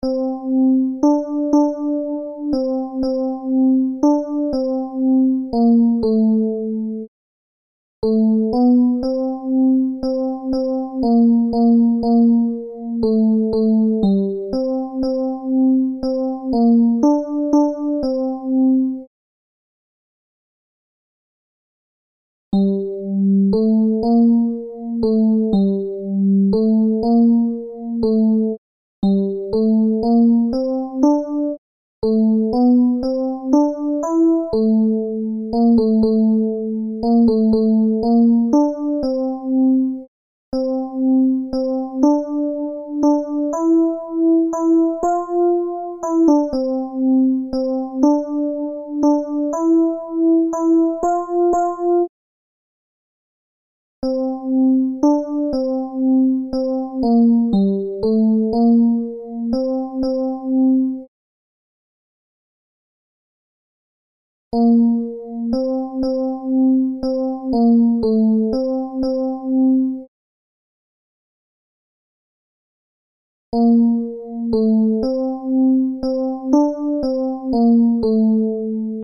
Ténors
pre_du_tendre_tenors.MP3